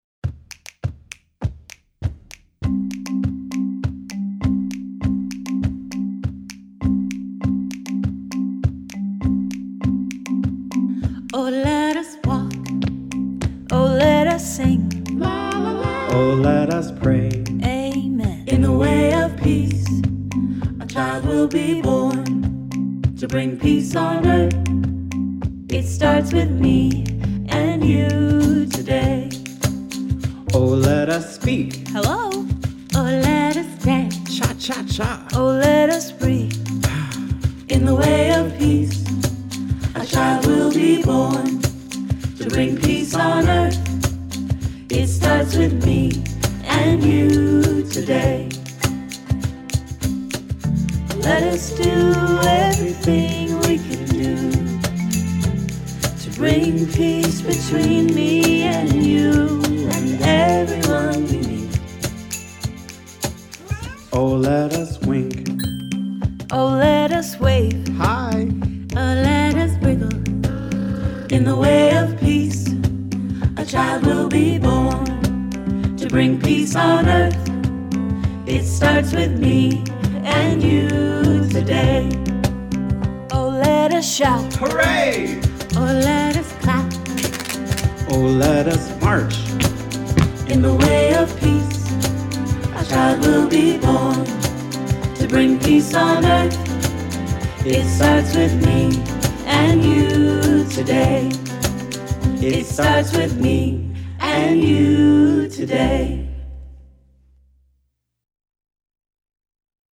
Children’s Songs for Advent and Christmas